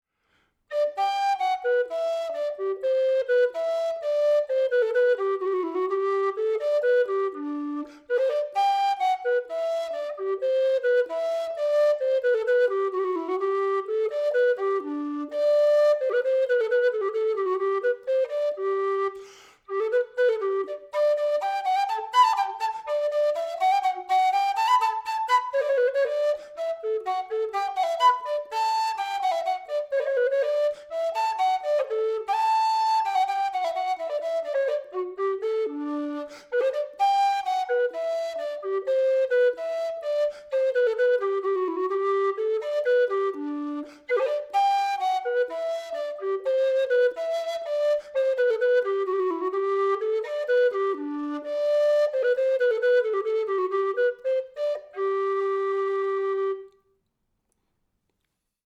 Wooden Low Whistle in D
The low whistle made of African blackwood creates a full sound which is rich in overtones and carries well.
These instruments have a more powerful sound than most low whistles, due to the way in which they are built.
The sound samples underneath the pictures demonstrate the wonderful but slightly different sound of these instruments.